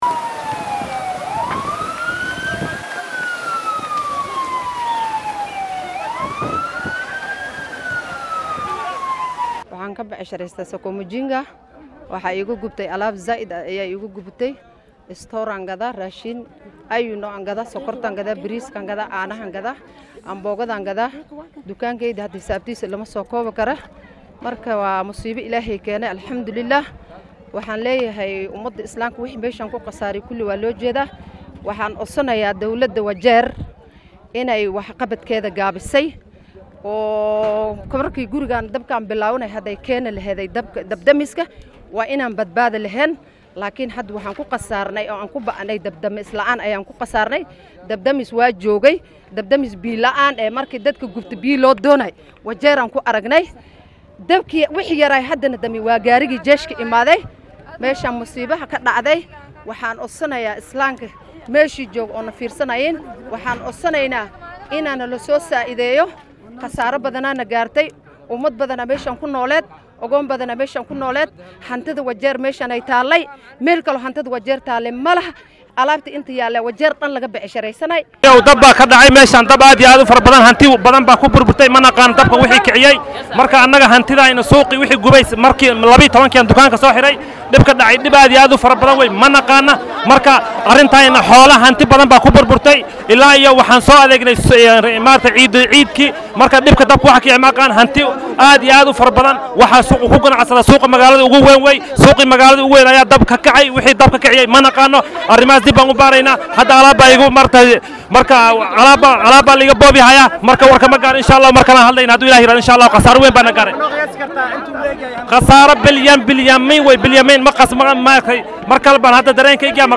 Qaar ka mid ah dadkii ku hanti beelay suuqaasi ayaa dareenkooda sidatan u muujiyay.